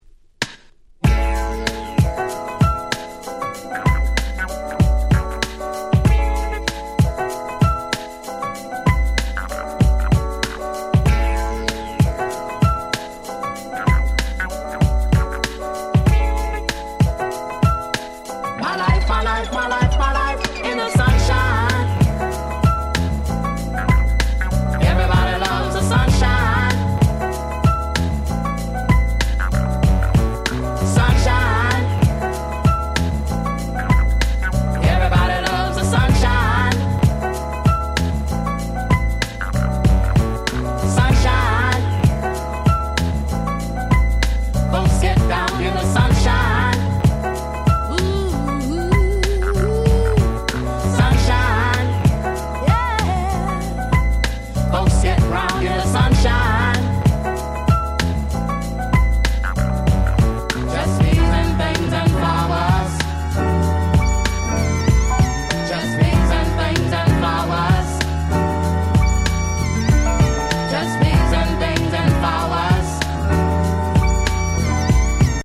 21' Nice Remix !!